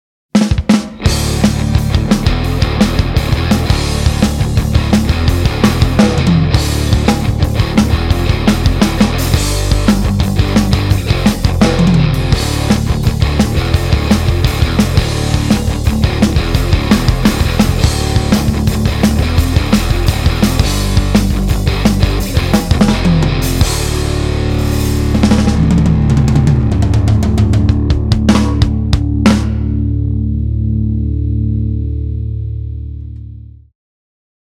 Drop D Soundcheck
Die Drums sind relativ holperig und ungenau... um die gehts mir auch nicht...
Also sonderlich brutal find ich die Gitarren jetzt nicht... eher putzig-kratzig und die haben ein unangenehmes Näseln im Sound.
Ich persönlich finde, dass den Gitarren komplett der Druck fehlt.
[g=118]Bass[/g] ist zu leise und der Sound/die Zerre ist Fasching Tanzmucke...
Wenig Verzerrung, sehr gut.
So aber ist der Ton schön definiert.